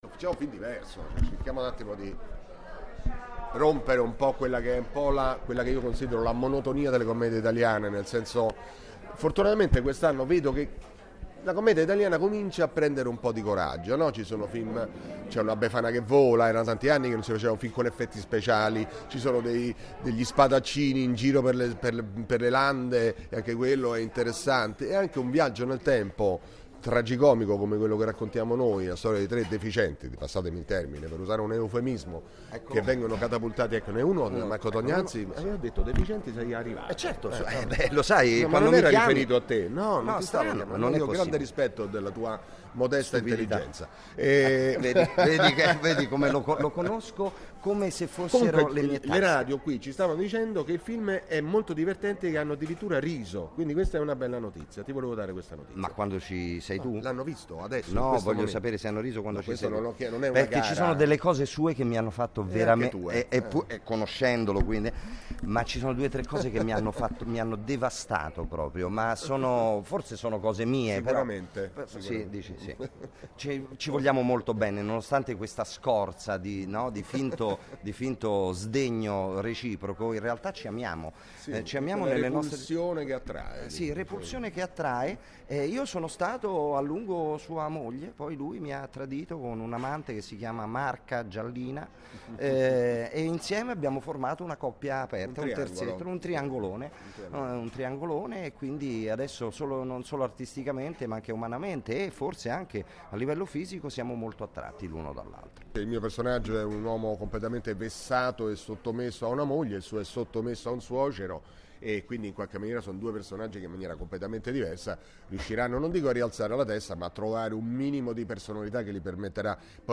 non-ci-resta-che-il-crimine-gassmann-e-tognazzi-parlano-del-film.mp3